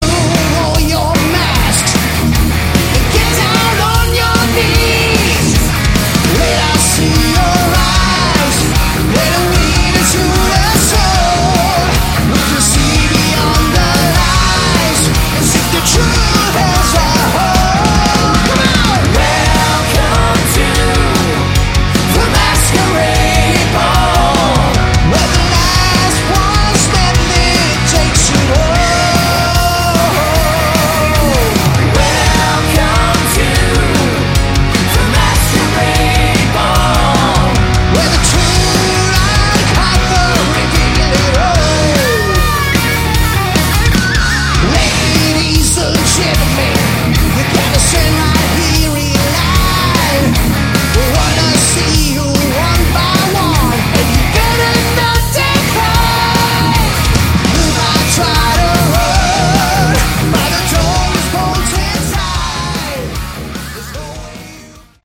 Category: Hard Rock
lead vocals
guitars
bass, vocals
keys, vocals
drums